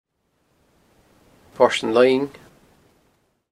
Click to hear placename audio